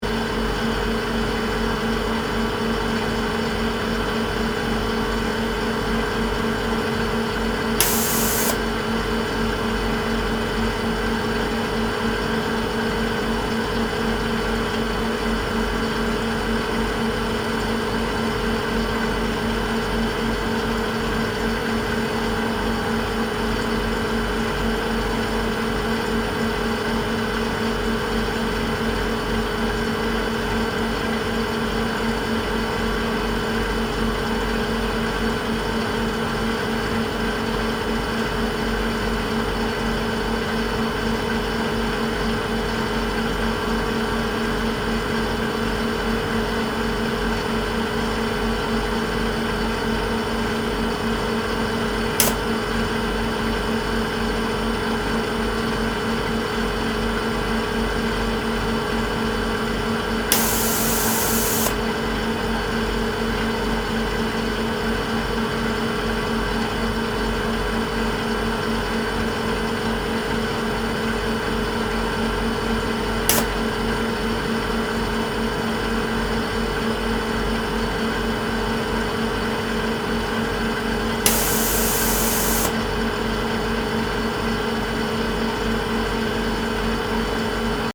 Soundscape: La Silla ESO 3.6-metre-telescope HARPS
Inside the High Accuracy Radial velocity Planet Searcher (HARPS) room, located on the third floor of the ESO 3.6-metre telescope building.
Soundscape Mono (wav)